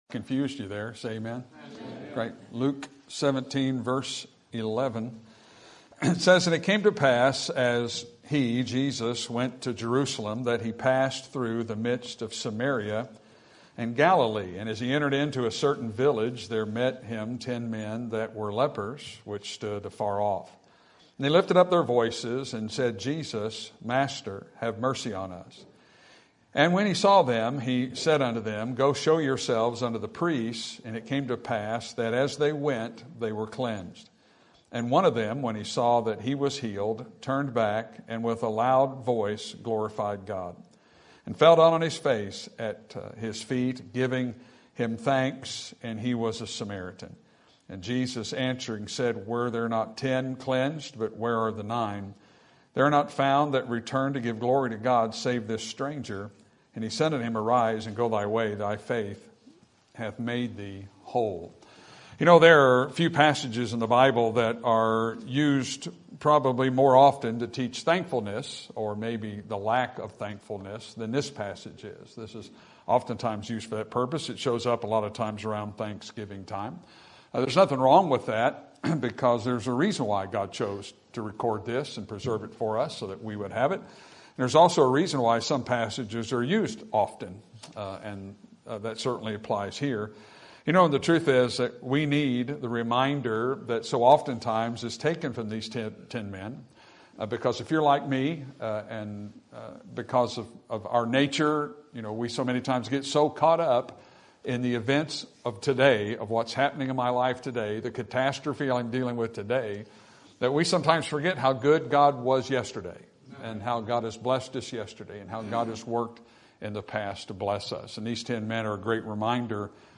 Sermon Topic: General Sermon Type: Service Sermon Audio: Sermon download: Download (19.89 MB) Sermon Tags: Luke Thanksgiving God Life